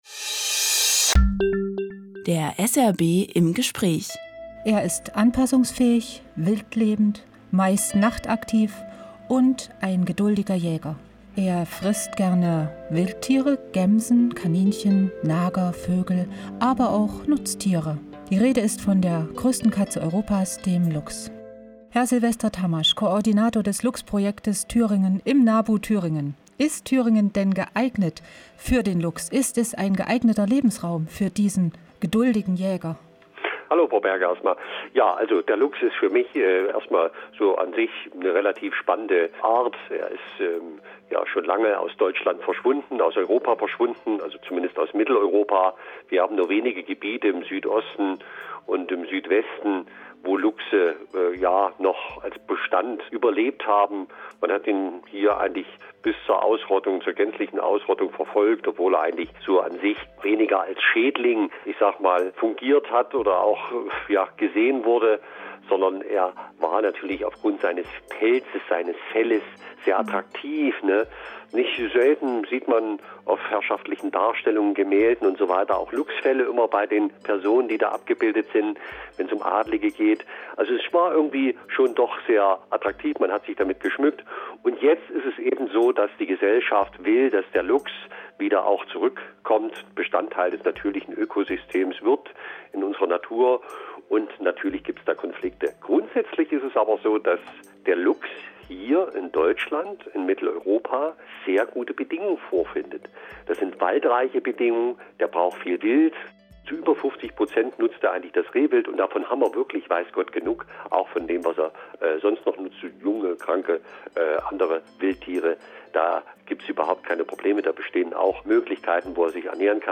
20.12.2017: Fühlt sich der Luchs wohl bei uns in Thüringen? - Radiointerview